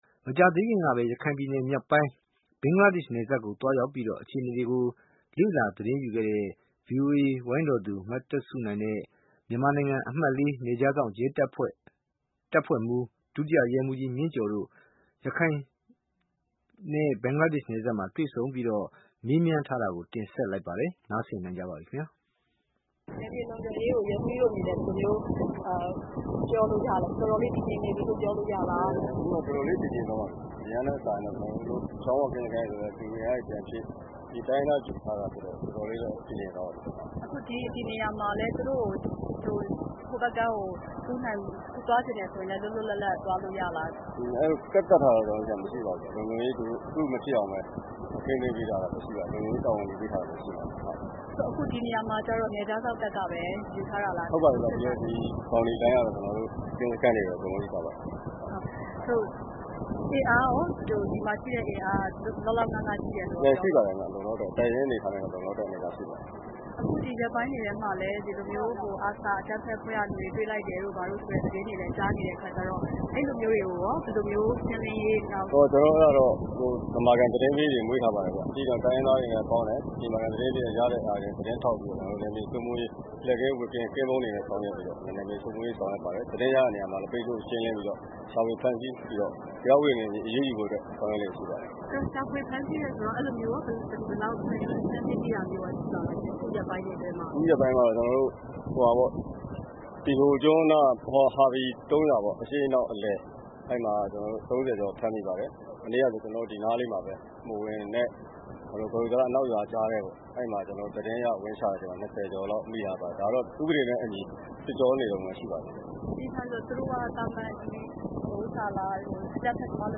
ရခိုင်နယ်စပ်လုံခြုံရေး နယ်ခြားစောင့်ရဲတပ်ဖွဲ့မှူး နဲ့ VOA တွေ့ဆုံမေးမြန်း